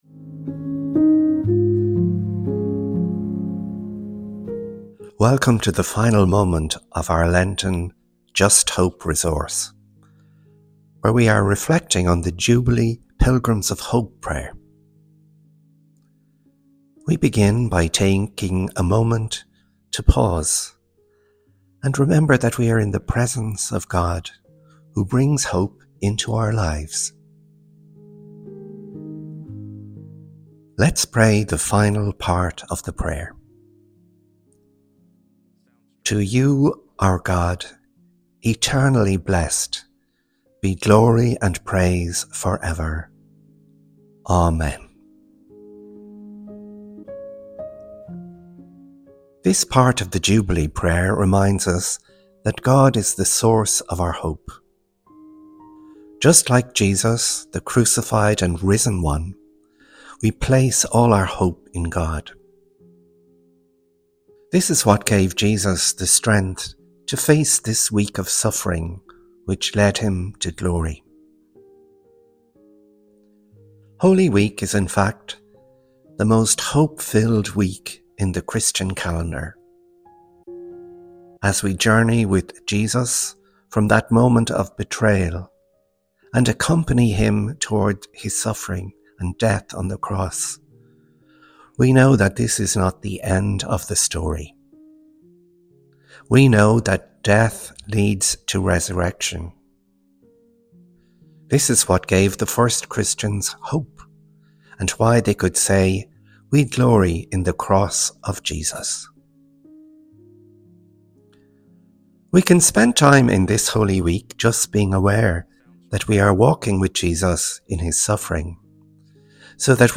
A moment of guided reflection
A moment of intercessory prayer